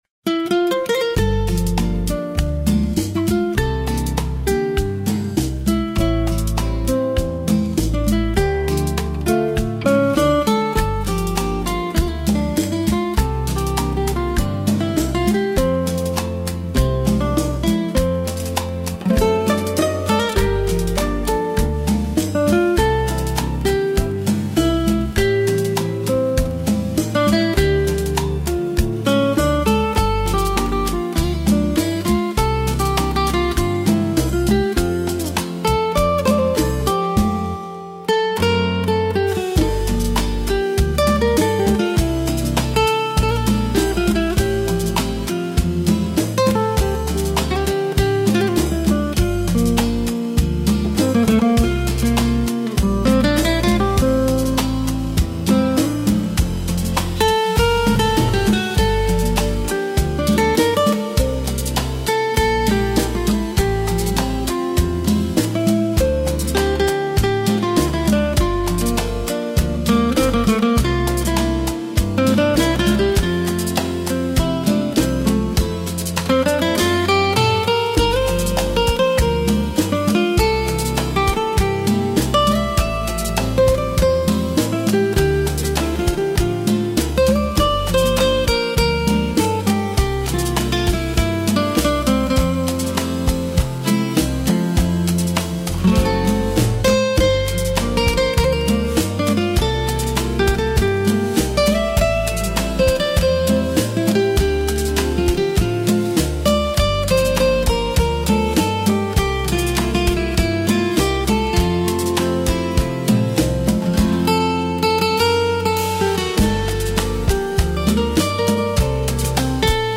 MÚSICA E ARRANJO: IA) INSTRUMENTAL